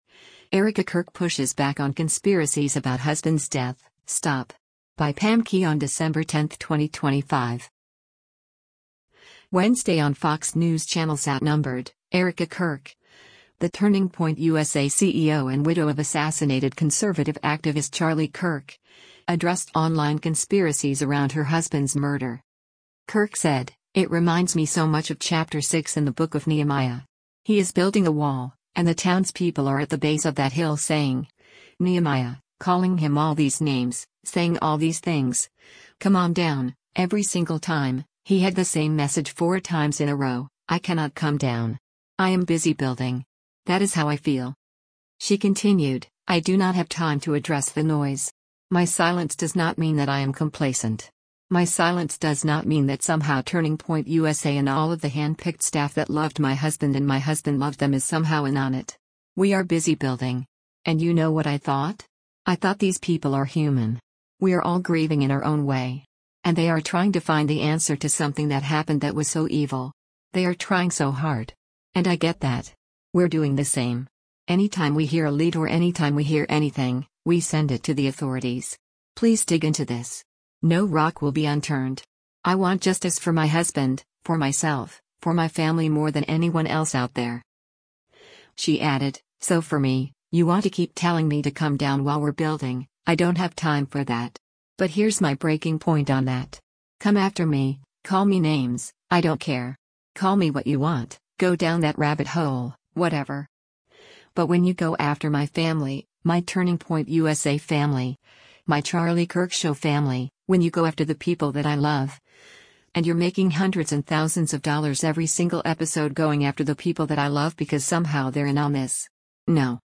Wednesday on Fox News Channel’s “Outnumbered,” Erika Kirk, the Turning Point USA CEO and widow of assassinated conservative activist Charlie Kirk, addressed online conspiracies around her husband’s murder.
Host Harris Faulkner said, “You know, I have to say it: I’ve never seen you like this.”